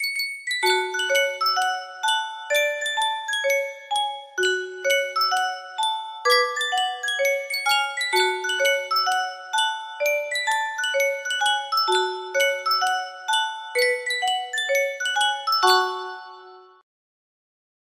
BPM 96